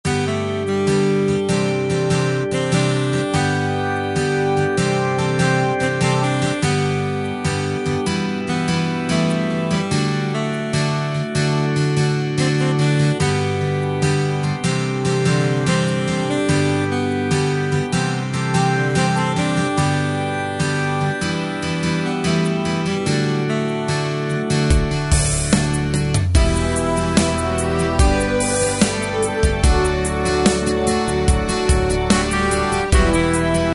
Tempo: 73 BPM.
MP3 with melody DEMO 30s (0.5 MB)zdarma